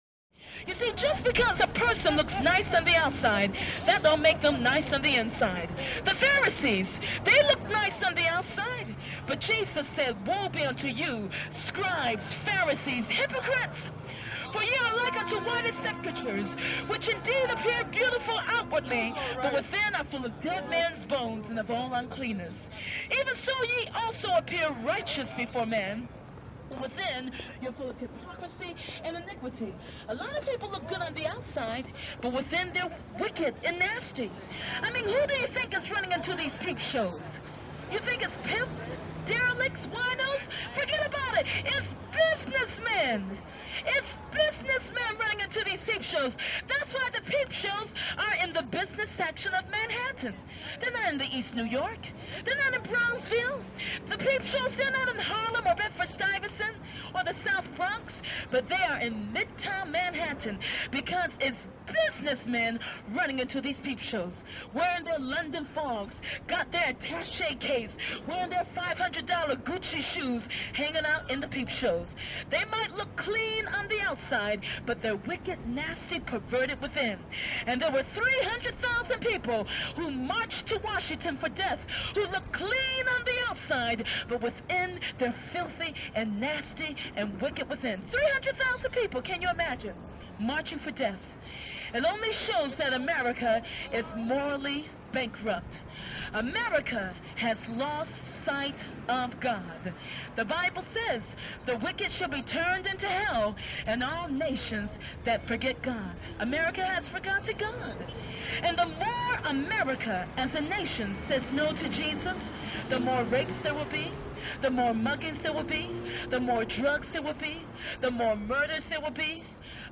FemaleStreetPreach CT021501
Female Street Preacher Through Megaphone Or Small Speaker, Close. Talks About Moral Bankruptcy Businessmen And Peep Shows. Heavy Traffic Ambience Close To Distant With Honks, Bus By, Brake Squeak